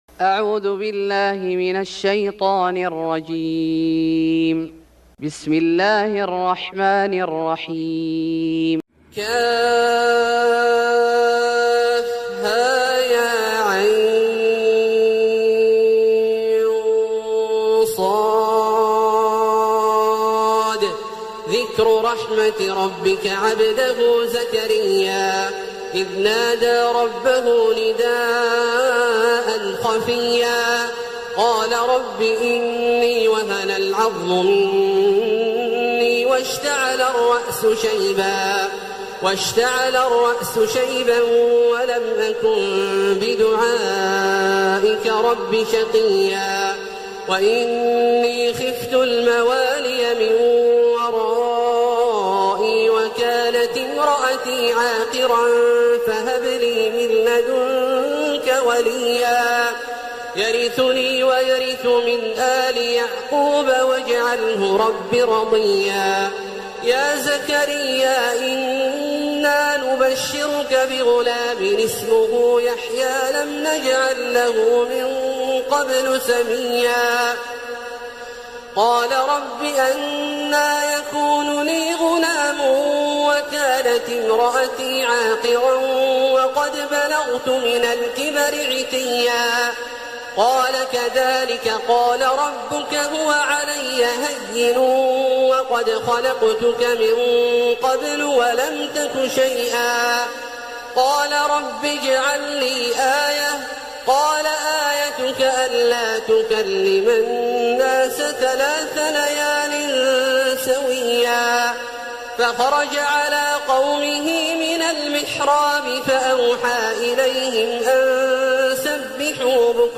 سورة مريم Surat Maryam > مصحف الشيخ عبدالله الجهني من الحرم المكي > المصحف - تلاوات الحرمين